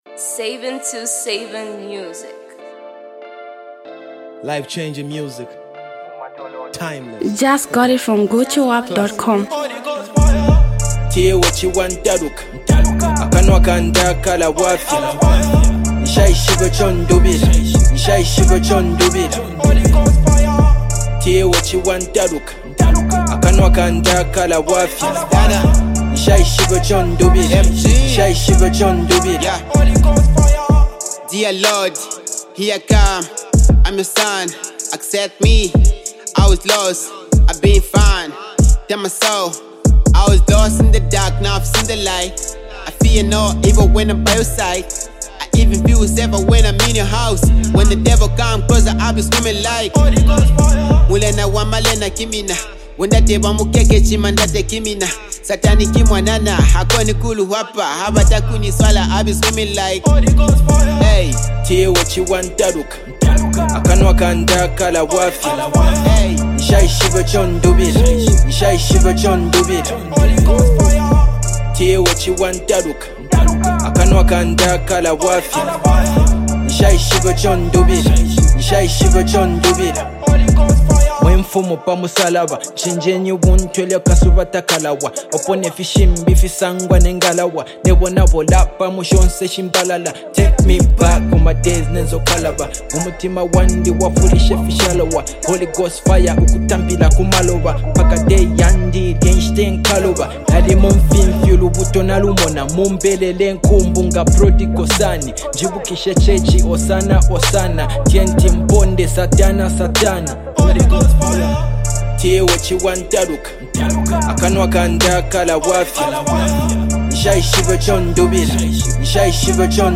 is that Soul-Stirring Gospel Anthem
’ An energetic artist who adds depth and flavor to the song.
a soul-stirring gospel anthem